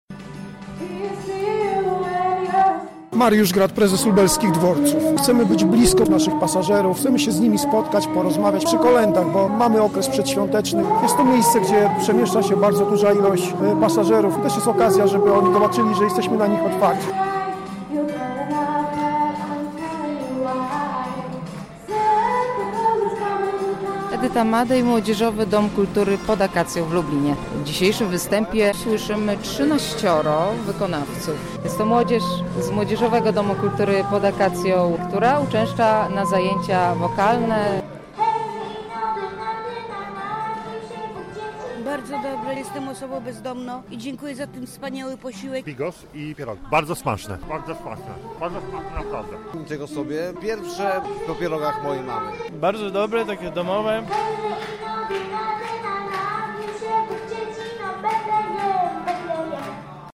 Kolędy w wykonaniu dzieci i młodzieży rozbrzmiewały na lubelskim dworcu.
Na lubelskim dworcu autobusowym przy alei Tysiąclecia rozbrzmiały kolędy w wykonaniu dzieci i młodzieży korzystających z zajęć w Młodzieżowym Domu Kultury Pod Akacją.
Kolędowanie dworzec